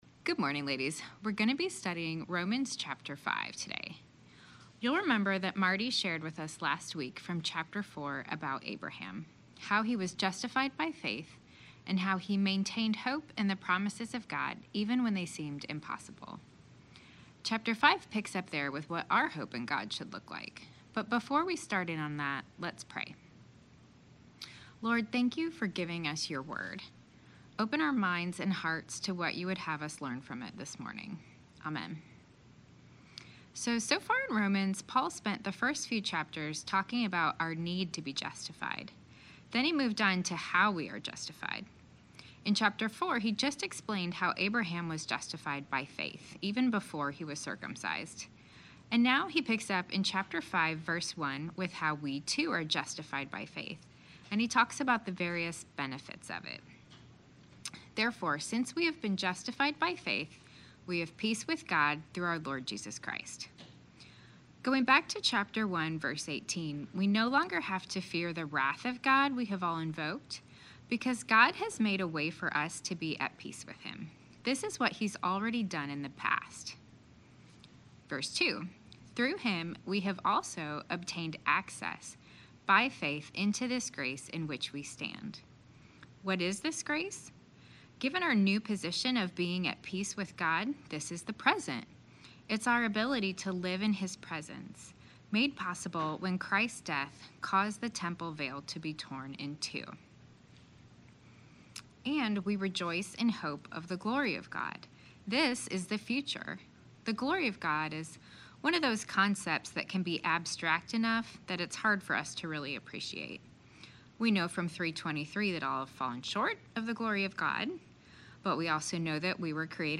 Women of the Word Wednesday Teaching Lesson 5: Romans 5 Nov 01 2023 | 00:26:19 1x 00:00 / 00:26:19 Subscribe Share RSS Feed Share Link Embed